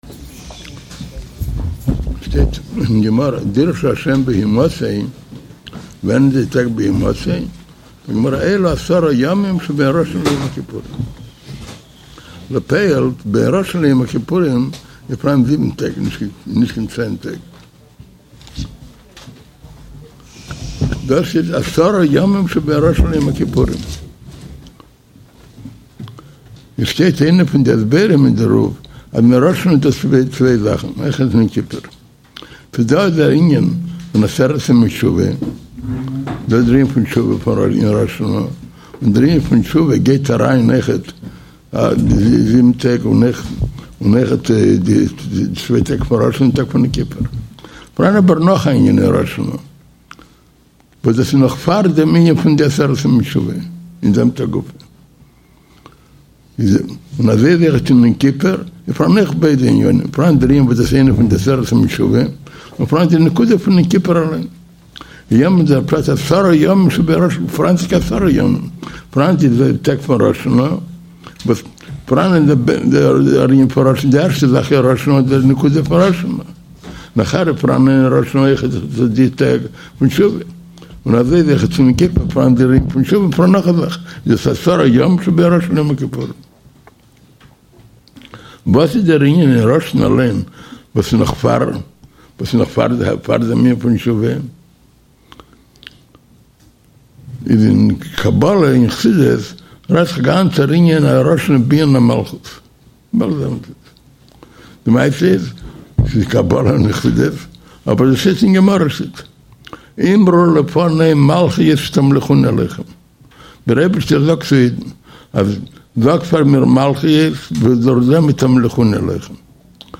שיעור במהותו של ראש השנה
שיעור הכנה על ראש השנה